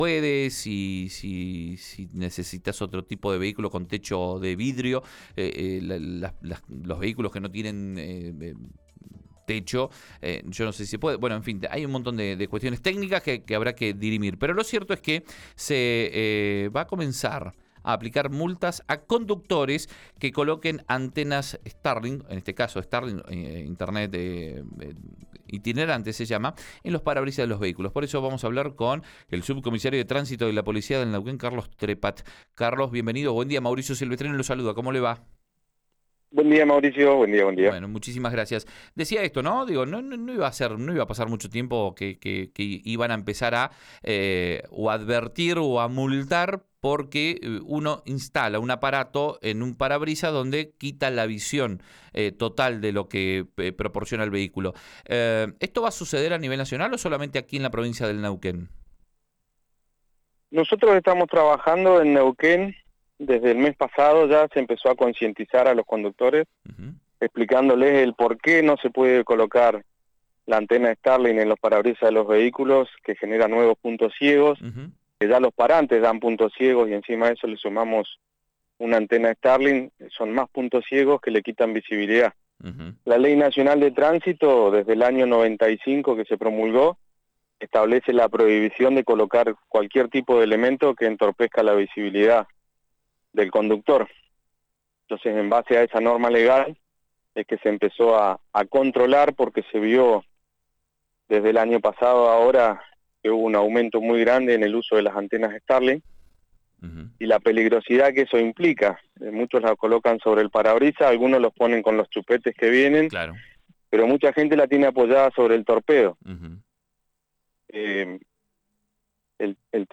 en RN Radio